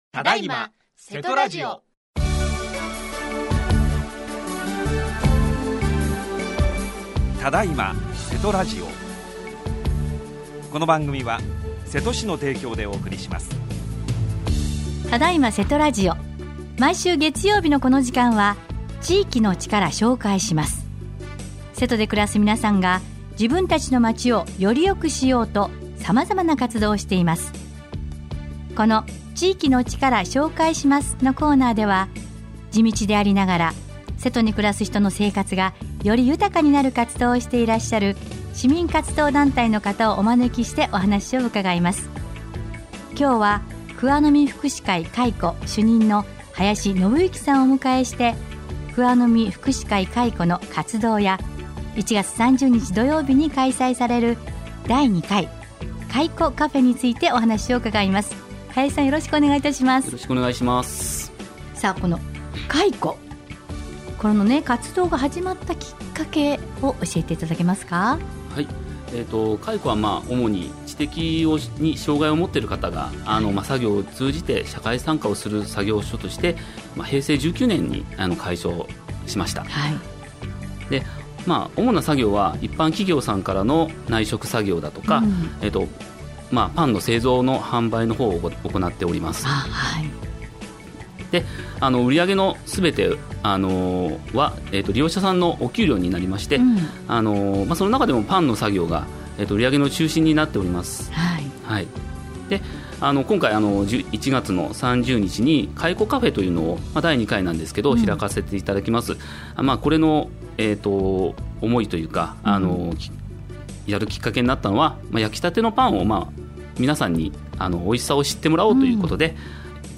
28年1月11日（月） 毎週月曜日は、〝地域の力 紹介します〝 このコーナーでは、地道でありながら、 瀬戸に暮らす人の生活がより豊かになる活動をしていらっしゃる 市民活動団体の方をお招きしてお話を伺います。